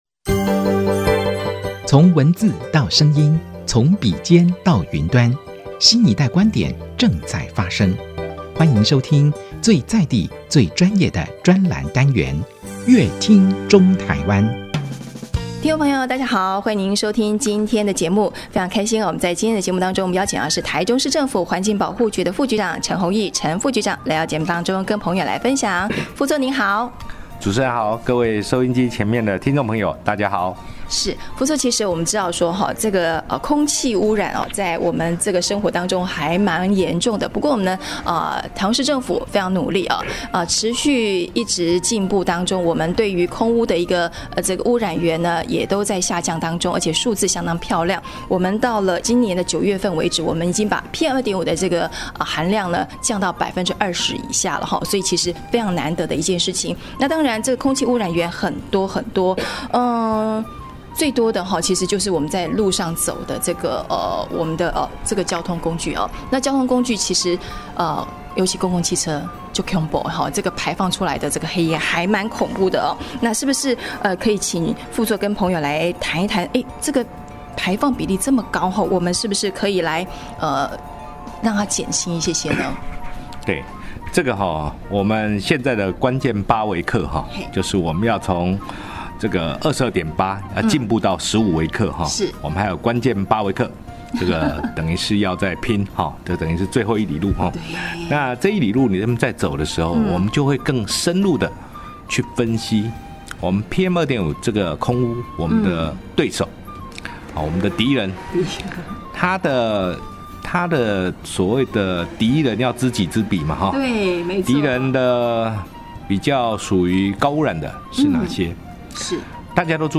本集來賓：臺中市政府環境保護局陳宏益副局長 本集主題：外埔綠能生態園把圾垃變黃金 本集內容： 最近台中的天空老